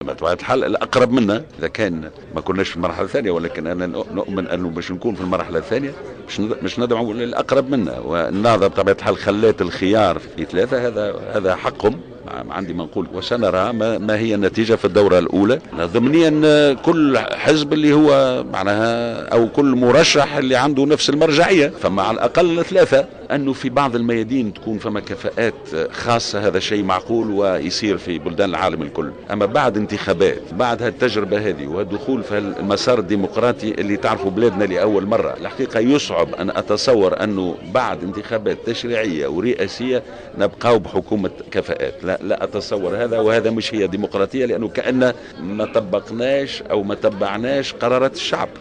Par ailleurs, Kamel Morjane a estimé, lors d’une conférence de presse tenue ce jour même, que ses chances de remporter les élections sont considérables.